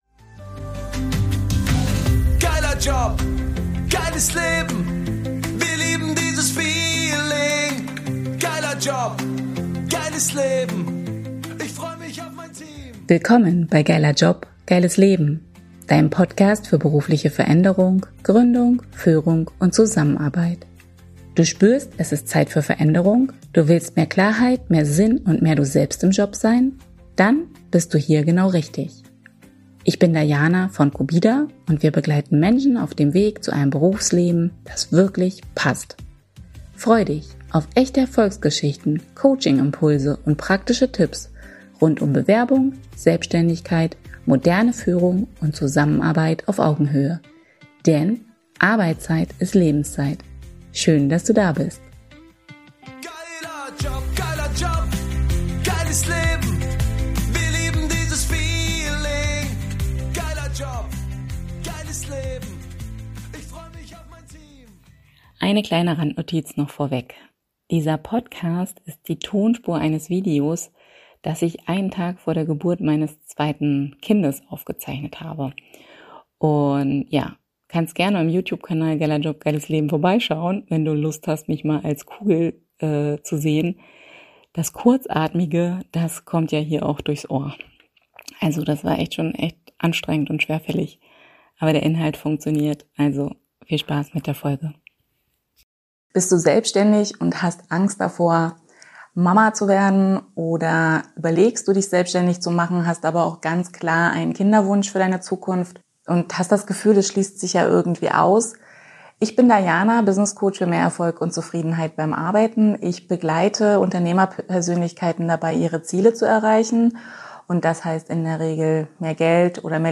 Diese Podcastfolge ist die Tonspur dieses Videos.